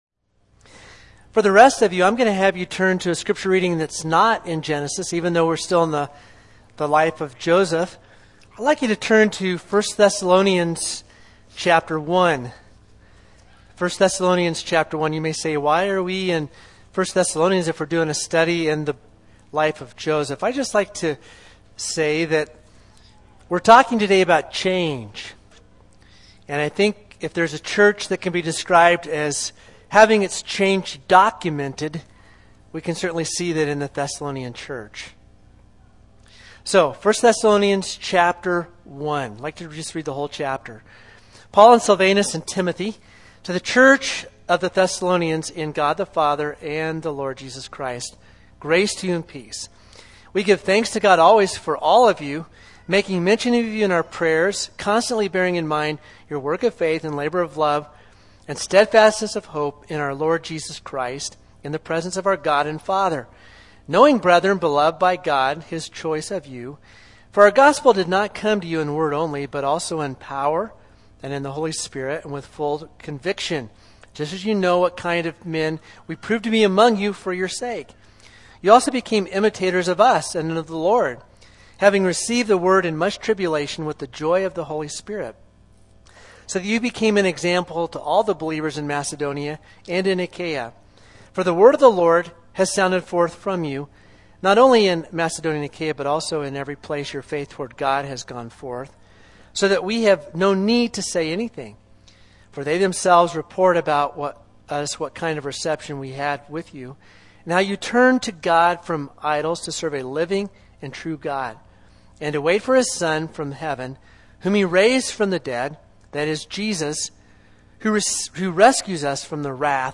4_25-2021-sermon.mp3